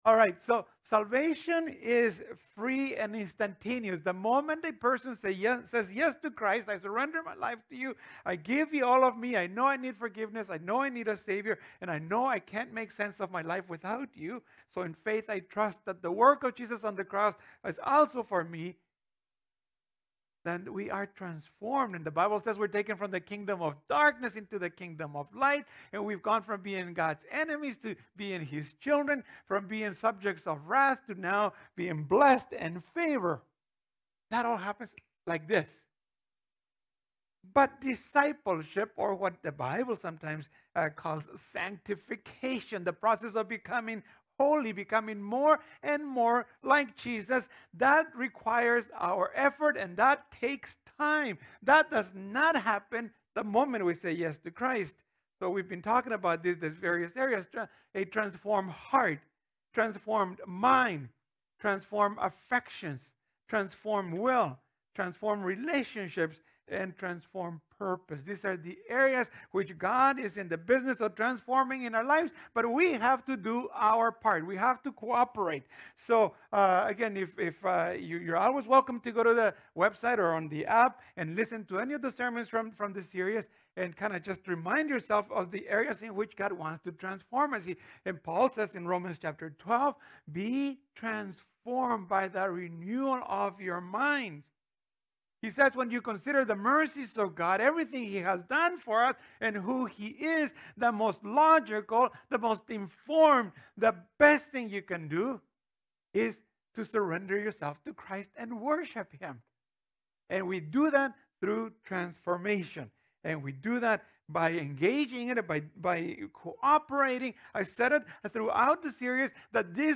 This is the final sermon in the Transformed series.